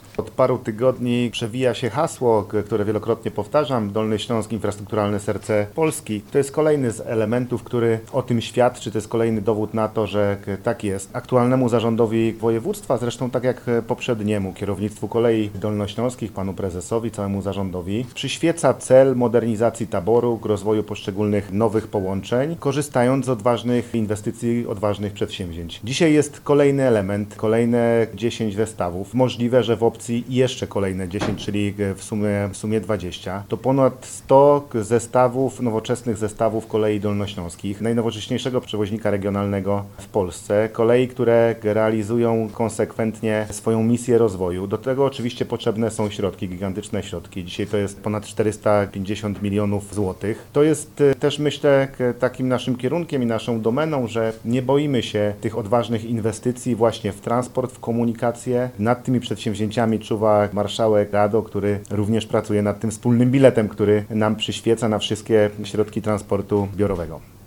Miło mi poinformować, że do co najmniej 35 sztuk wzrośnie największa w Polsce flota pojazdów typu Elf – wszystkie będą jeździć w dolnośląskich barwach – powiedział Paweł Gancarz, marszałek województwa dolnośląskiego.